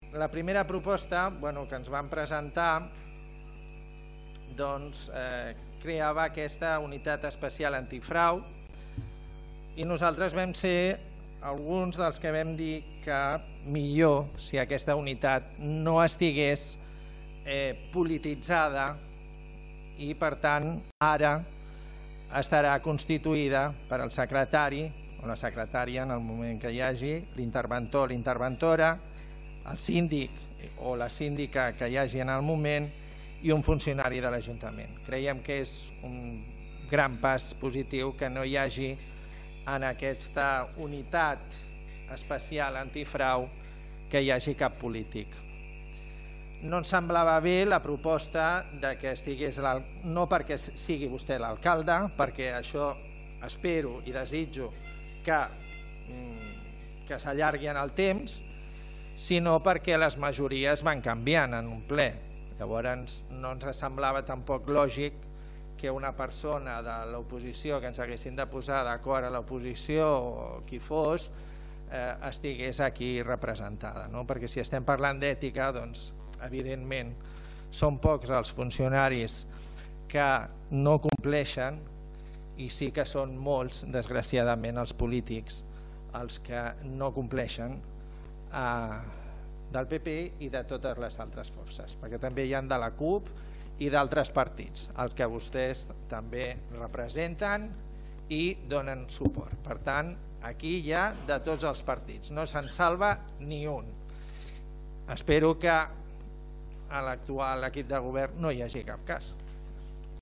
El portaveu del PP, Diego Parra, va explicar que el seu partit va ser un dels que van proposar que la Unitat antifrau no estigués polititzada: